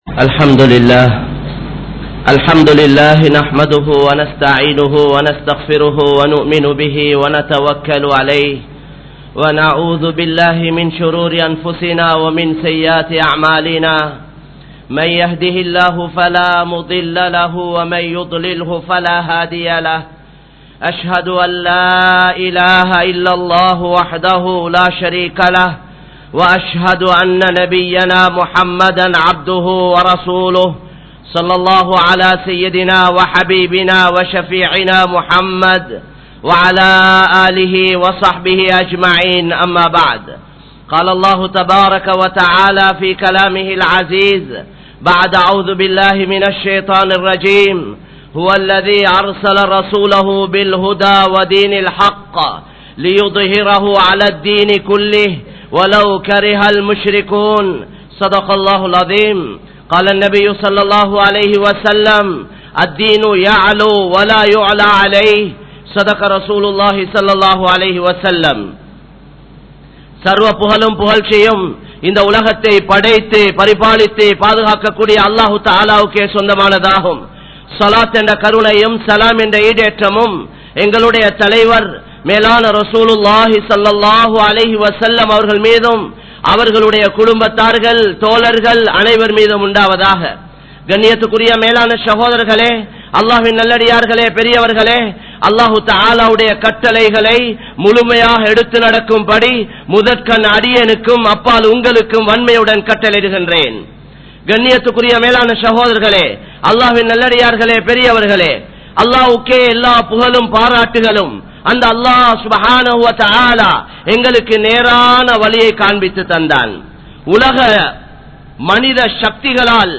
Hithayaththitku Sonthakkaaran Allah (ஹிதாயத்திற்கு சொந்தக்காரன் அல்லாஹ்) | Audio Bayans | All Ceylon Muslim Youth Community | Addalaichenai
Colombo 03, Kollupitty Jumua Masjith